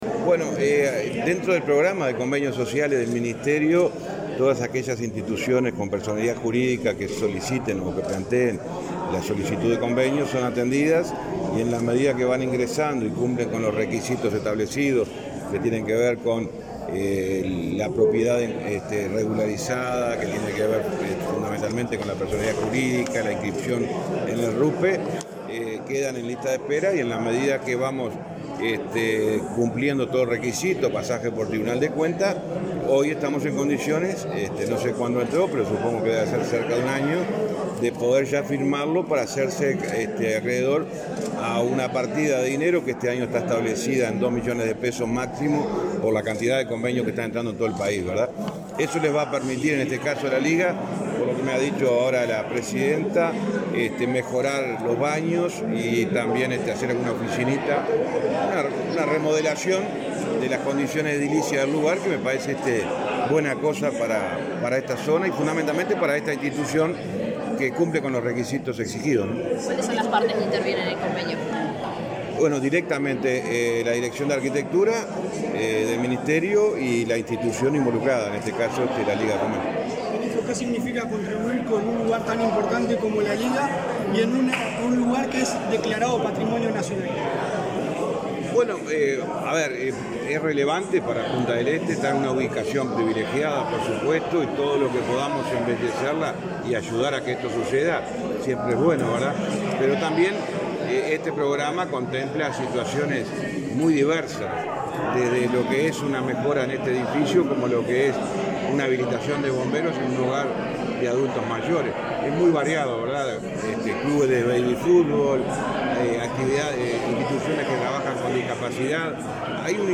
Declaraciones del ministro de Transporte, José Luis Falero
Declaraciones del ministro de Transporte, José Luis Falero 28/12/2023 Compartir Facebook X Copiar enlace WhatsApp LinkedIn El ministro de Transporte, José Luis Falero, dialogó con la prensa en Maldonado, durante una recorrida en la que firmó varios convenios con instituciones sociales.